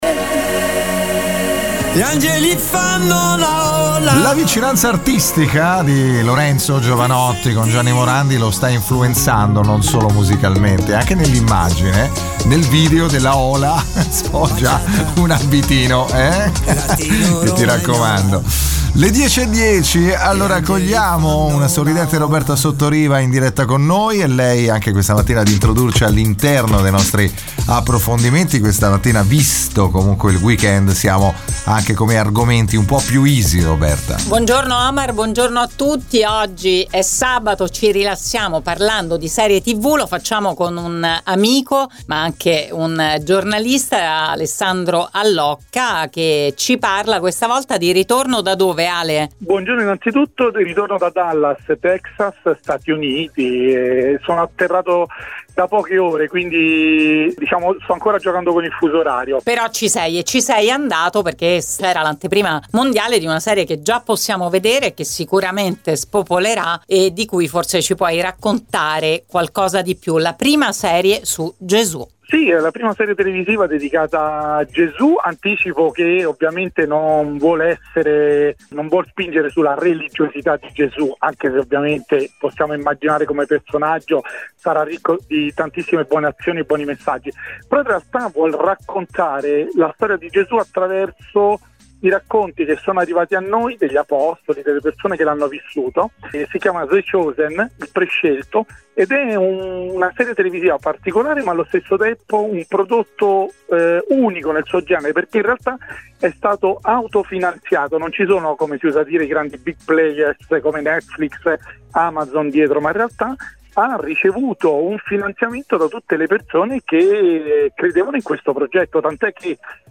Ne abbiamo parlato con lui su Radio Immagine